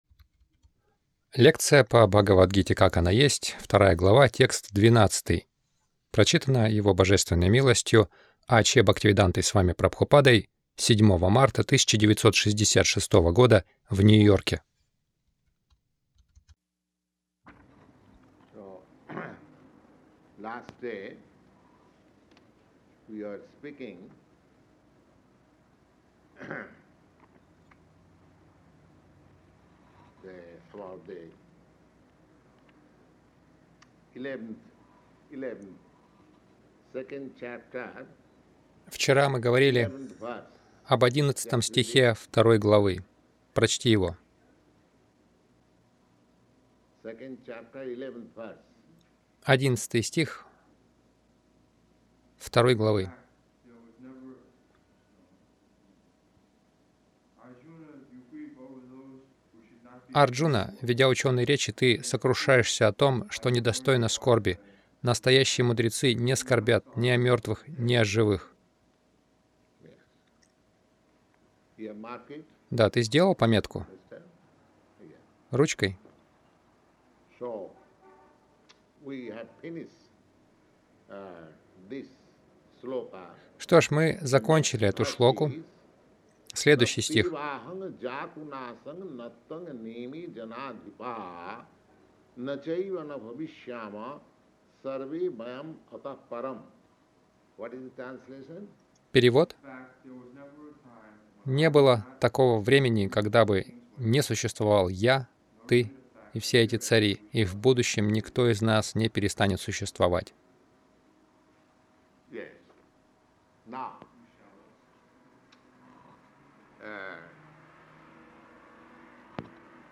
Милость Прабхупады Аудиолекции и книги 07.03.1966 Бхагавад Гита | Нью-Йорк БГ 02.12 — Душа всегда остается личностью Загрузка...